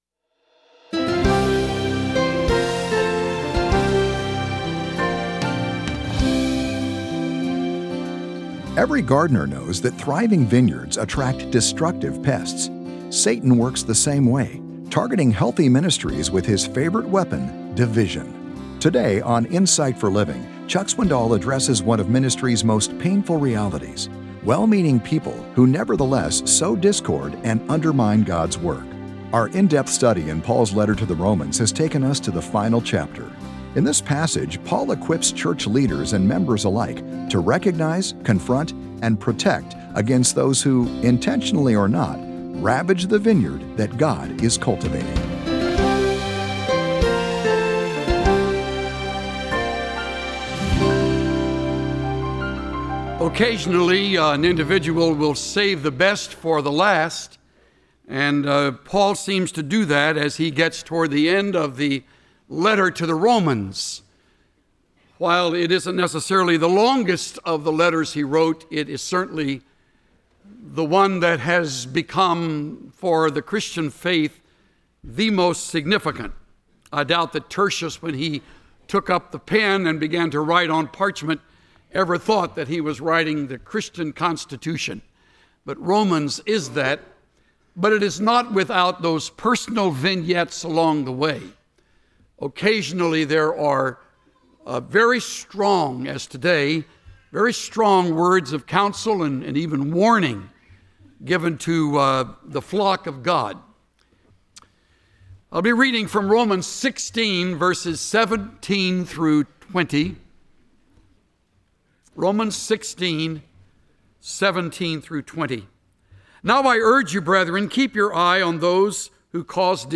Join the millions who listen to the lively messages of Chuck Swindoll, a down-to-earth pastor who communicates God’s truth in understandable and practical terms—with a good dose of humor thrown in. Chuck’s messages help you apply the Bible to your own life.